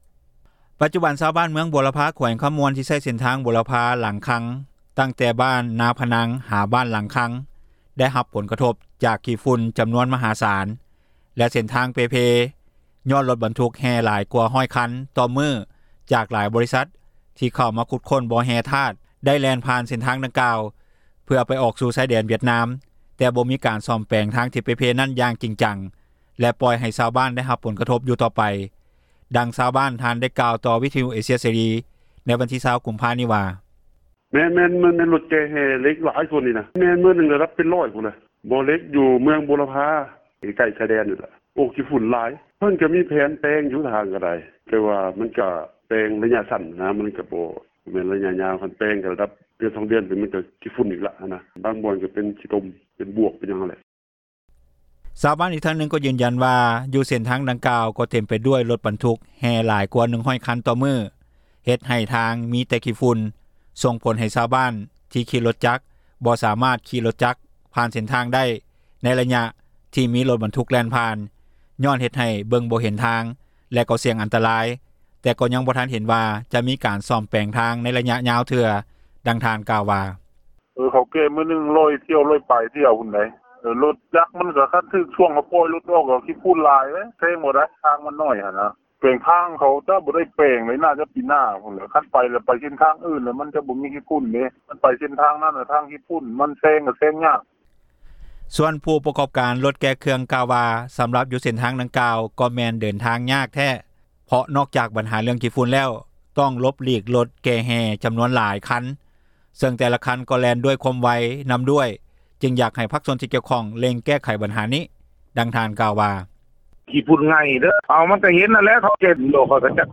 ດັ່ງຊາວບ້ານ ທ່ານກ່າວຕໍ່ວິທະຍຸເອເຊັຍເສຣີ ໃນວັນທີ 20 ກຸມພາ ນີ້ວ່່າ:
ດັ່ງຊາວບ້ານ ນາງກ່າວວ່າ: